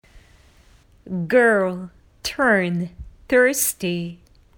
girl （少女） gɚːl
turn (回転させる）　tɚːn
thirsty（のどが渇いた）　θɚːsti
⇒ 発音見本は
strong schwa1.mp3